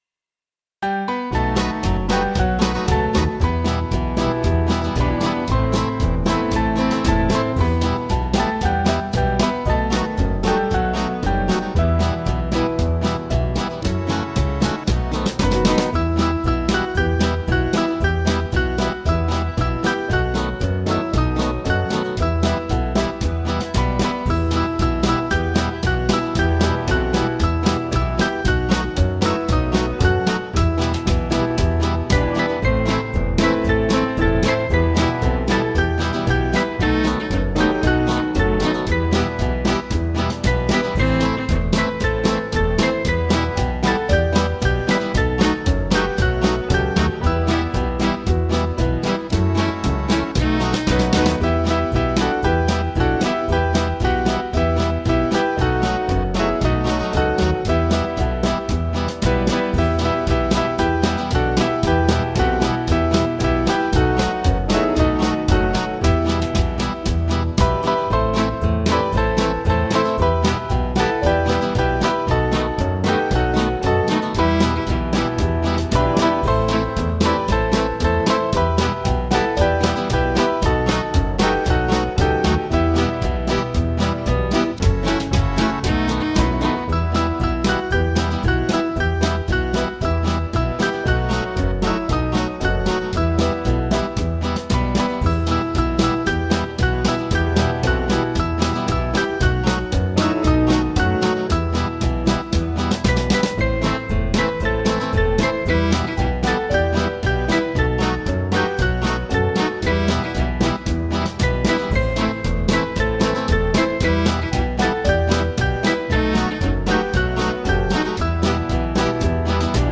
Small Band
(CM)   4/Em-Fm
Jewish Feel   465.6kb